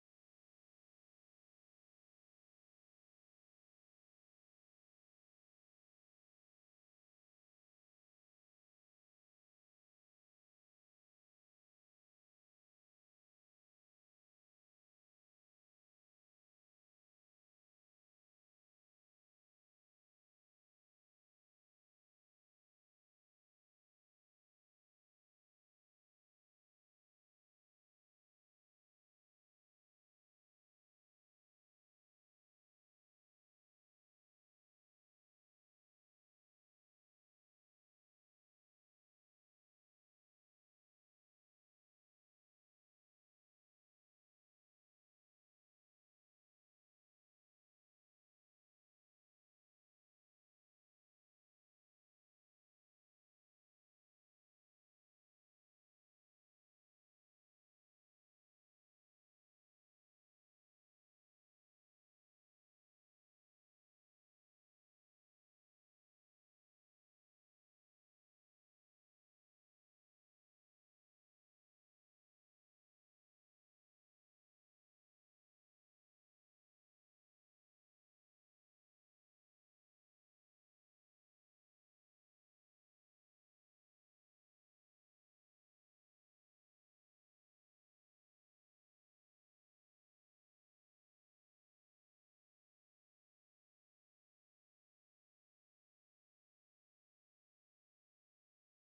海外マニアによるノイズレスのレストア音源を初収録！！
※試聴用に実際より音質を落としています。